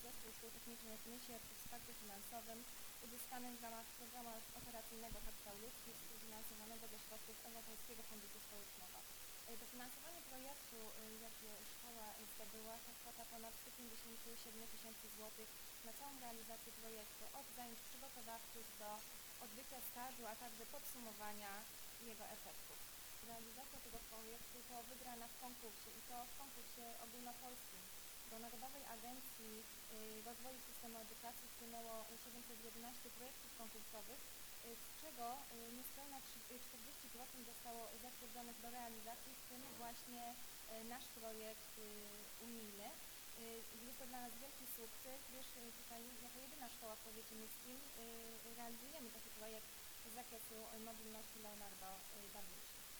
Nysa Online- wywiad